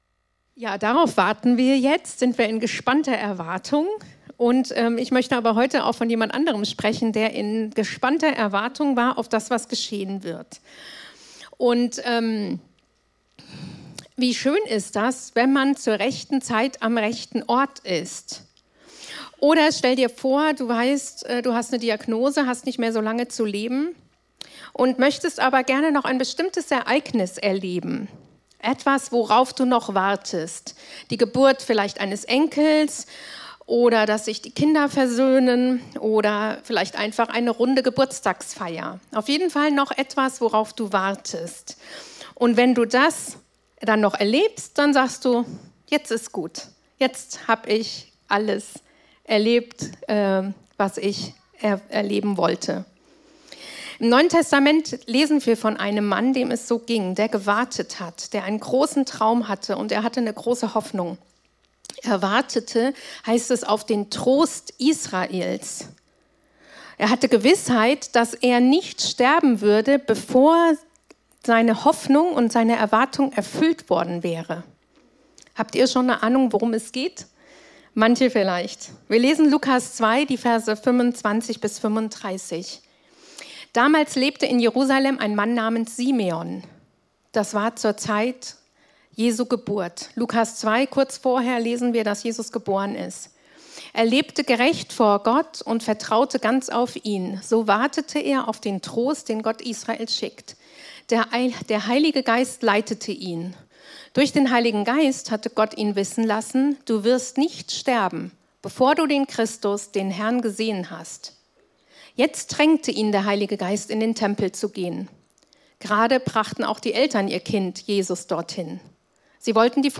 Predigten aus der freien Christengemeinde Die Brücke in Bad Kreuznach.